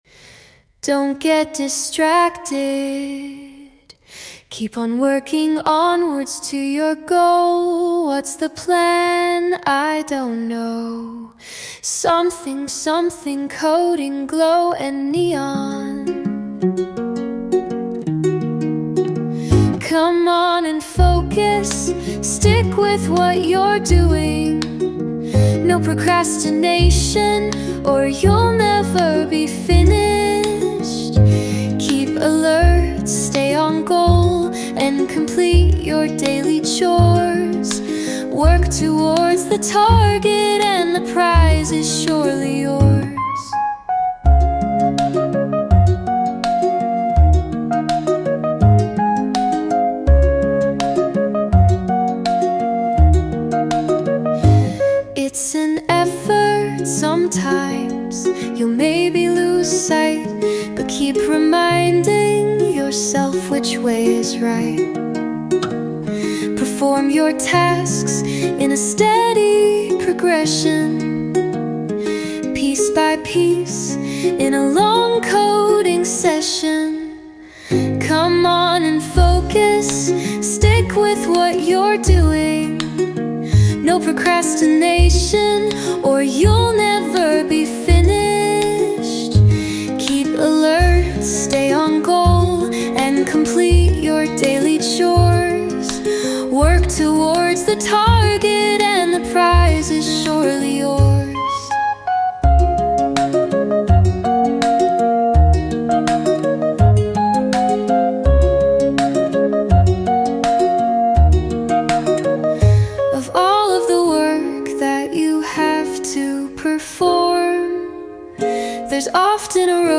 Sound Imported : Transindental Unplugged
Sung by Suno